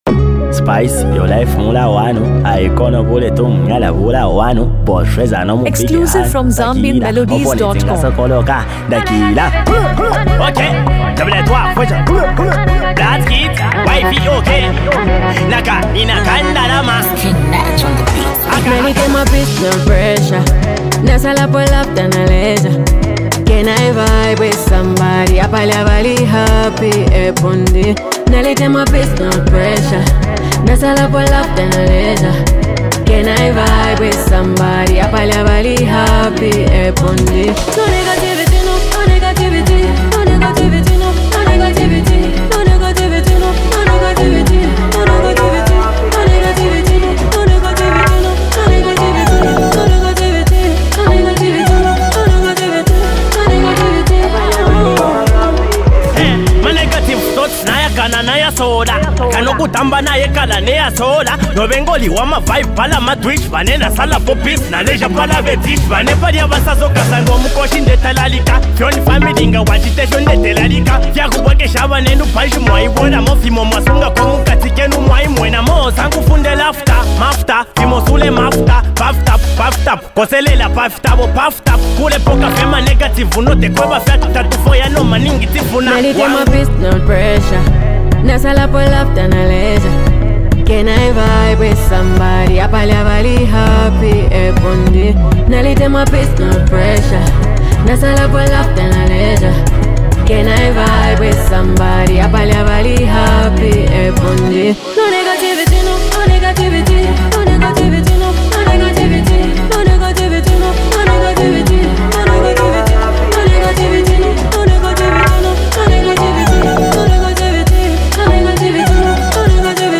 a fusion of vibrant beats and motivational lyrics